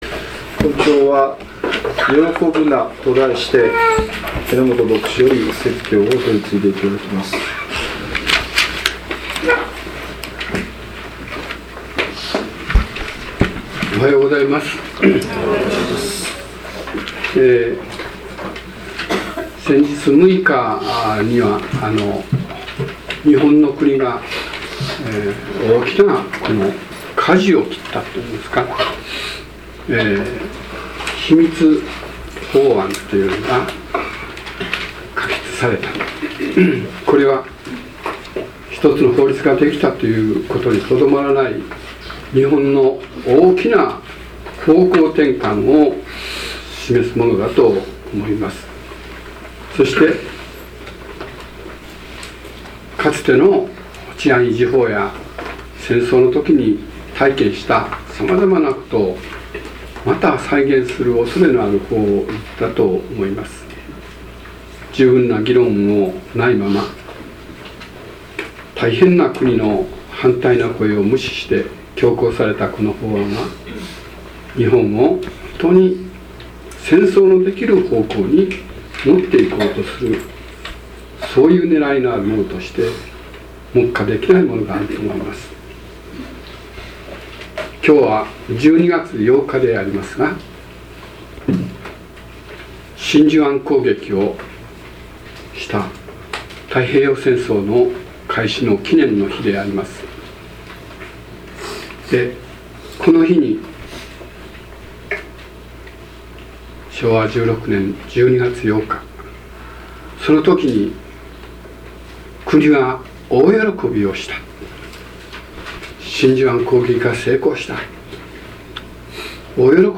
説教要旨 2013年12月8日 喜ぶな | 日本基督教団 世光教会 京都市伏見区